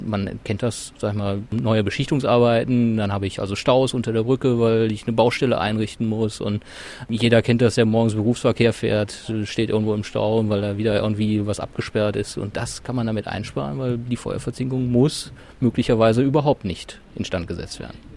O-Töne / Radiobeiträge, , , ,